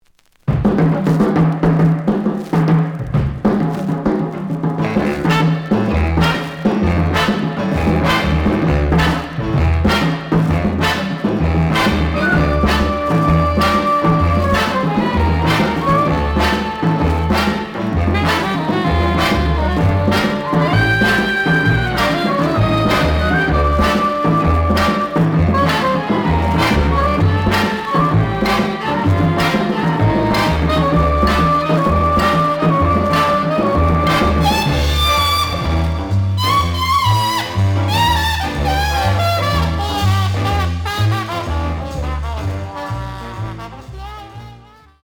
The audio sample is recorded from the actual item.
●Format: 7 inch
●Genre: Jazz Other
Some noise on fisrt half of B side, but almost plays good.